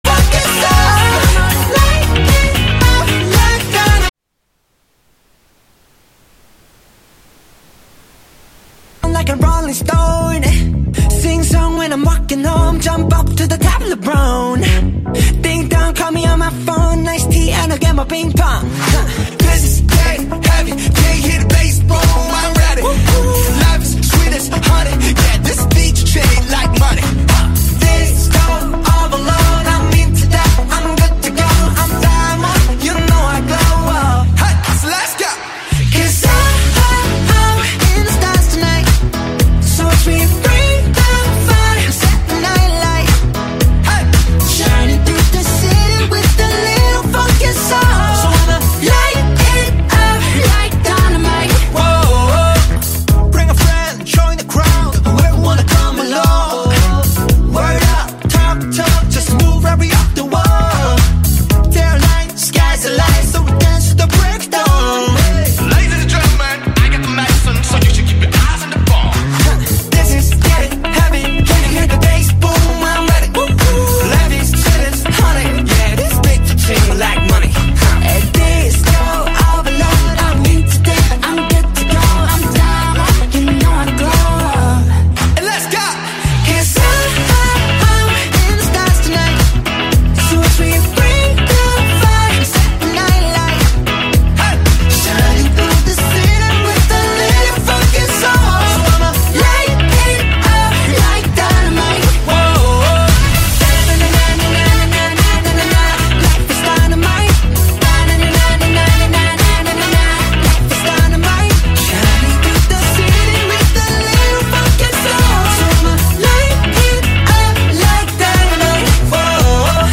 The new dance sounds for world consumption.
Radio station
Genre: Electronic , Pop , House